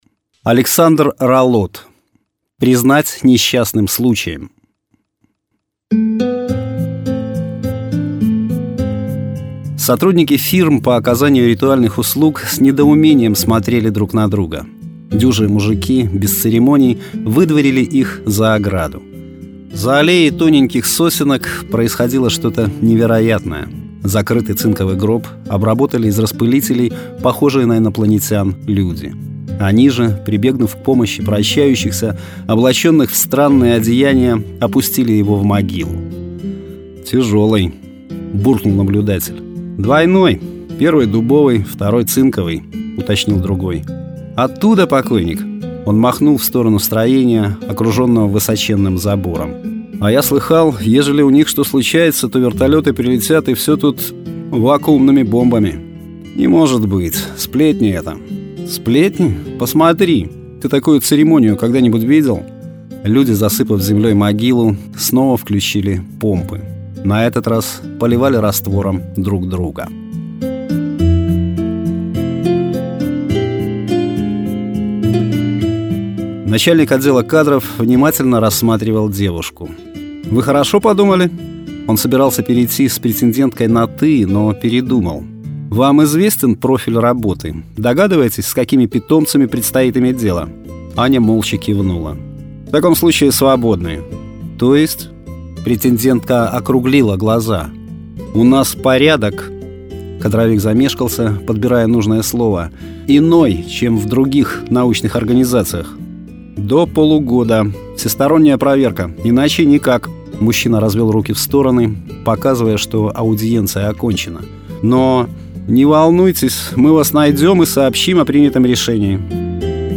Аудиорассказ
Жанр: Современная короткая проза